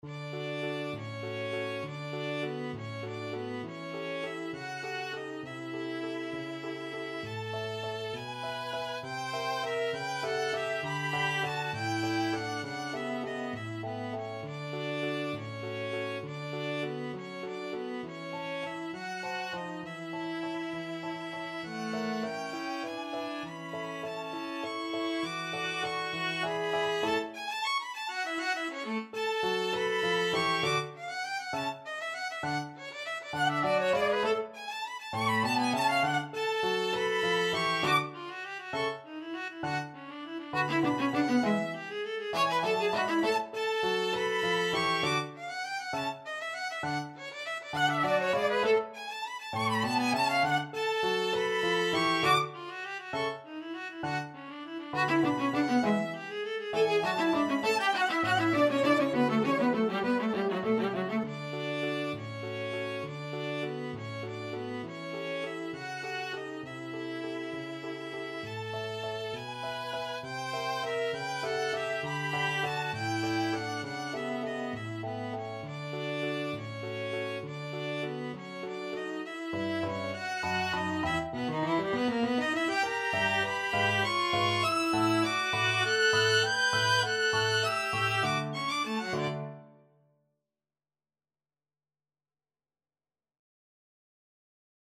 3/4 (View more 3/4 Music)
~=200 One in a bar
D major (Sounding Pitch) (View more D major Music for Violin-Viola Duet )
Violin-Viola Duet  (View more Intermediate Violin-Viola Duet Music)
Classical (View more Classical Violin-Viola Duet Music)